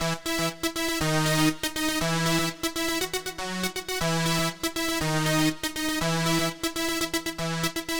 Power Pop Punk Keys 01b.wav